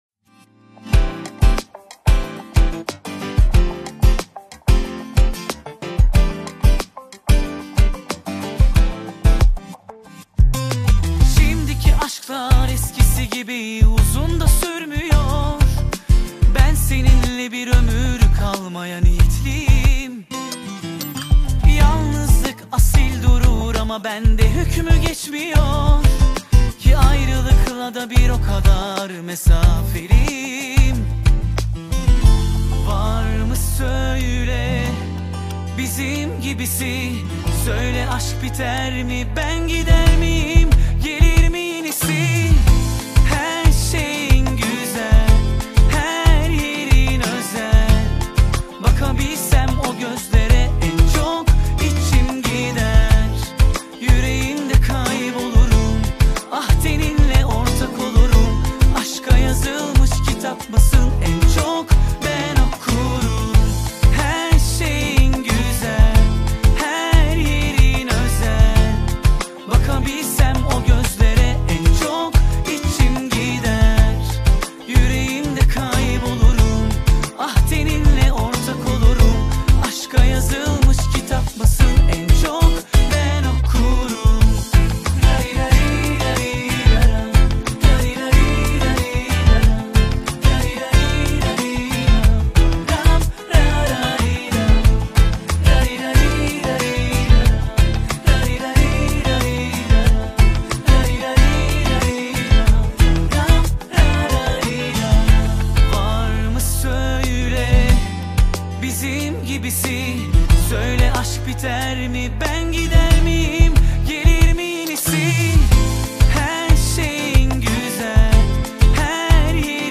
Турецкая музыка